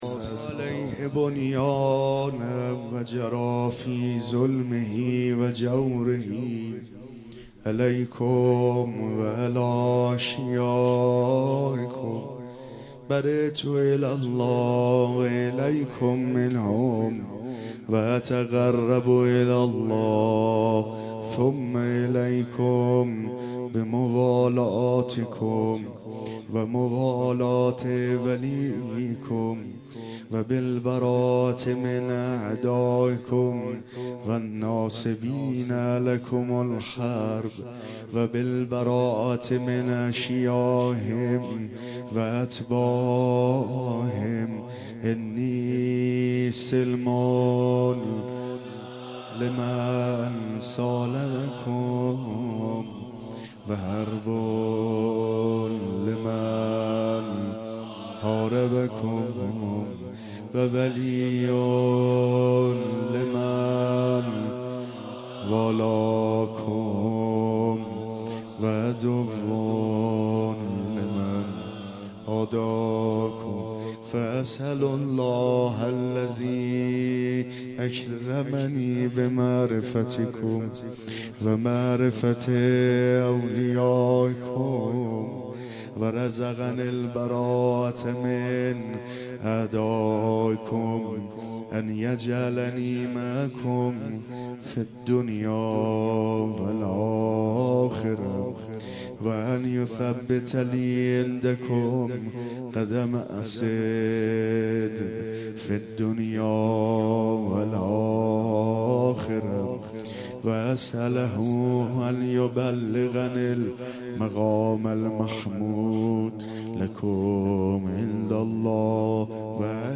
روضه امام حسین(ع)
سینه زنی/زمینه/شور